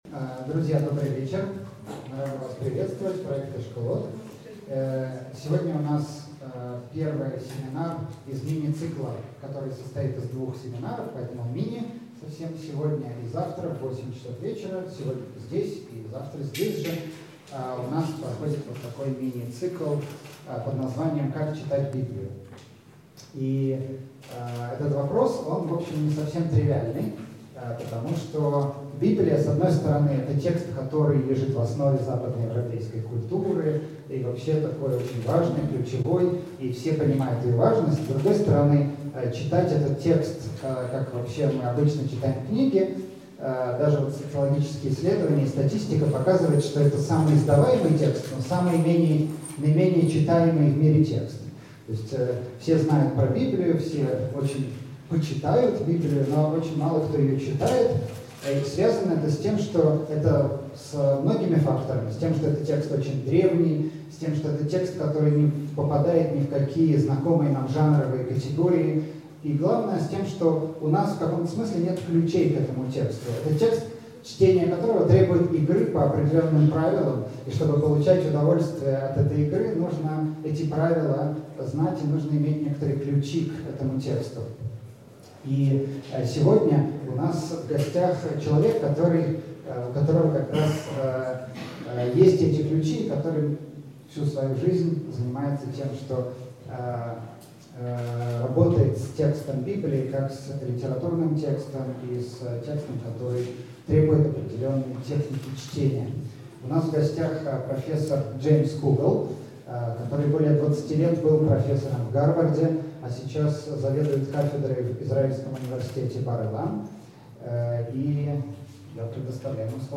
Аудиокнига Как читать Библию? Часть 1 | Библиотека аудиокниг